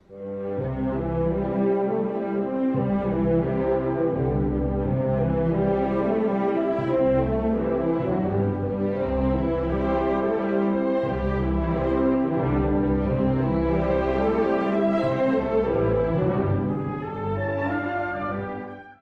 古い音源なので聴きづらいかもしれません！（以下同様）
第2楽章｜民族舞曲ふうの暖かみ
スケルツォですが、雰囲気は実に素朴。
南ドイツの舞曲「レントラー」を思わせる、民俗的で優しい音楽です。
中低音のメロディーがほんのり温かく、